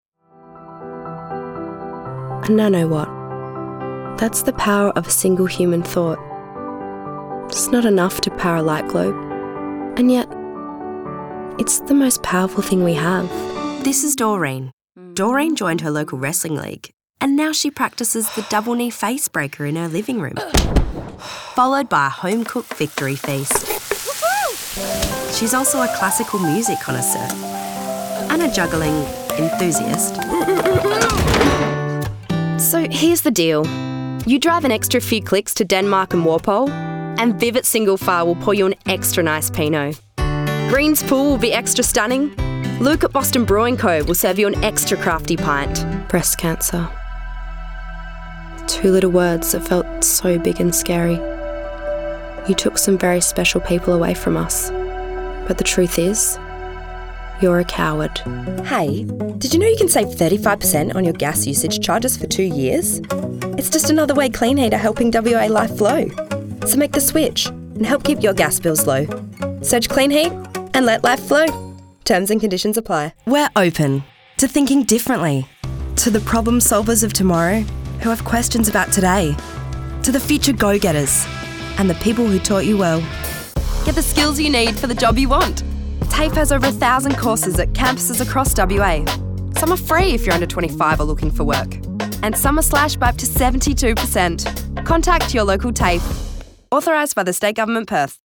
Female Voice Over Talent, Artists & Actors
Yng Adult (18-29) | Adult (30-50)